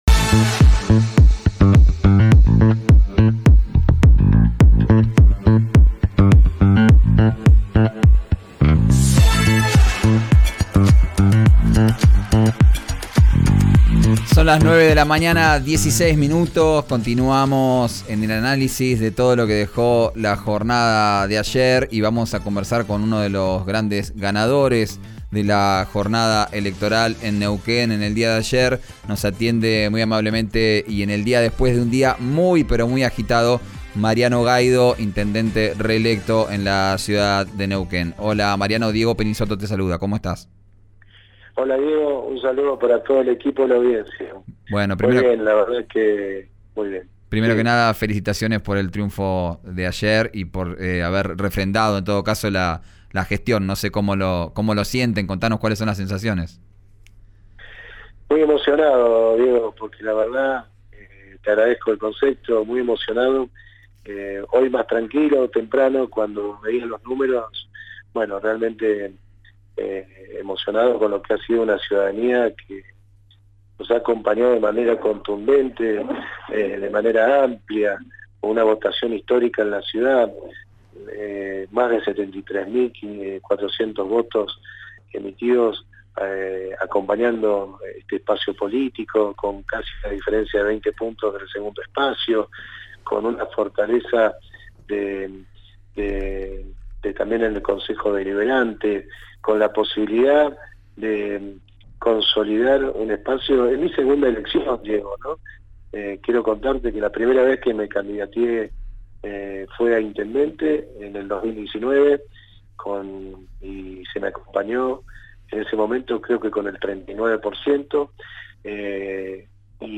Escuchá al intendente de Neuquén en RÍO NEGRO RADIO, que dijo cómo buscará que sea su gestión con otro signo político en la provincia.